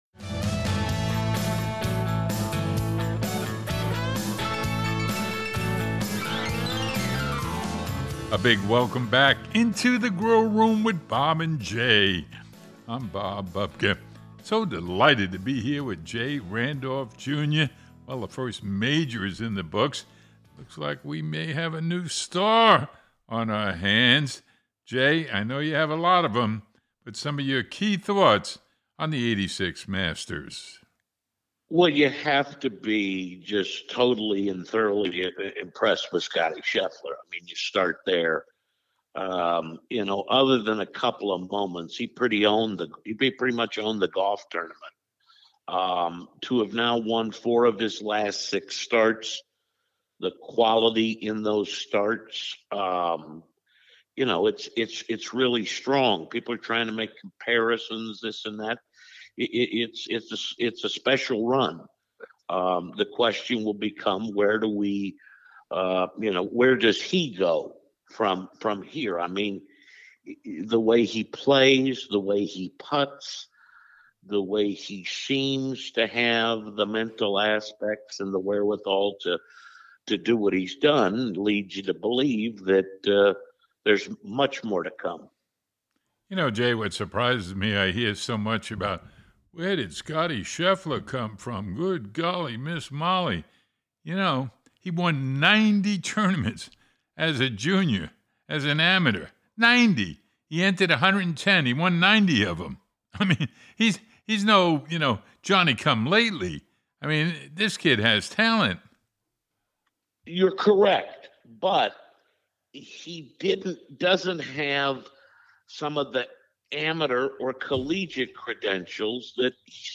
Scottie, Rory, Cam Smith, and Tiger comment on their 2022 Masters week.